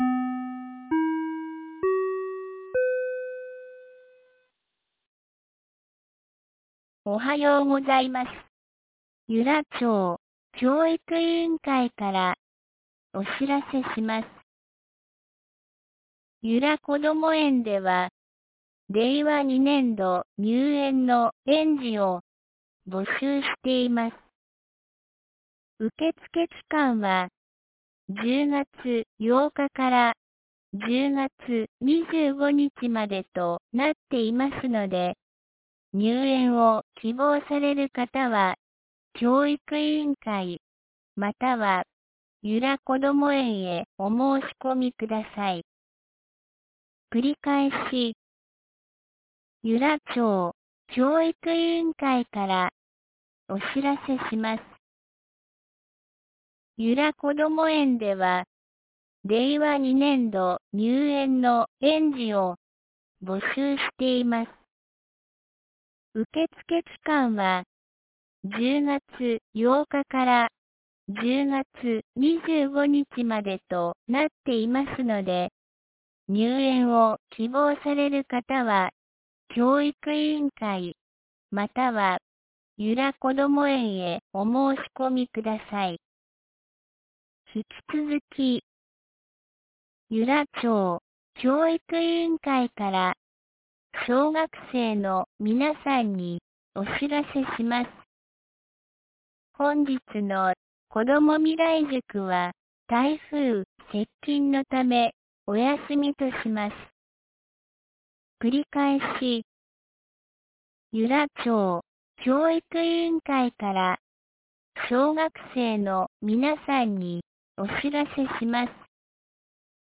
2019年10月12日 07時52分に、由良町から全地区へ放送がありました。
放送音声